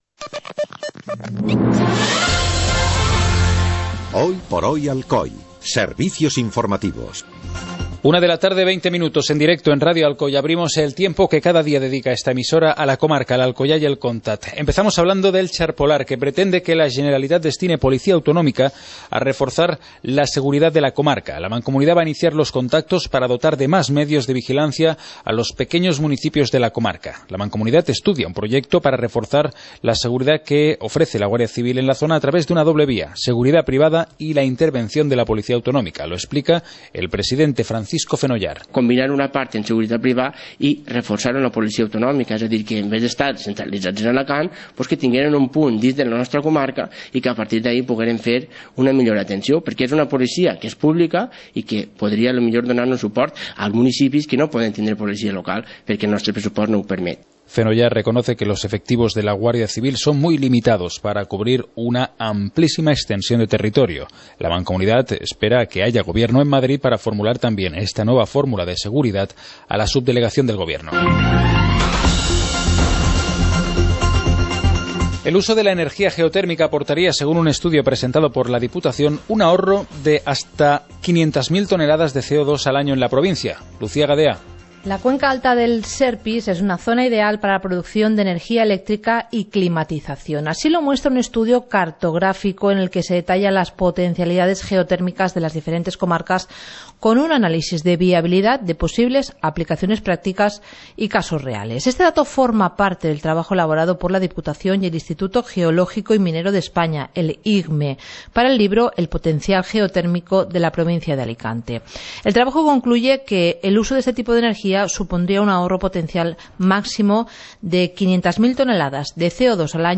Informativo comarcal - martes, 01 de marzo de 2016